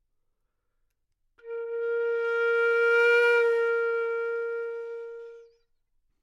长笛单音（吹得不好） " 长笛 Asharp4 baddynamics
描述：在巴塞罗那Universitat Pompeu Fabra音乐技术集团的goodsounds.org项目的背景下录制。单音乐器声音的Goodsound数据集。 instrument :: flutenote :: Asharpoctave :: 4midi note :: 58microphone :: neumann U87tuning reference :: 442goodsoundsid :: 3106 故意扮演坏动态的一个例子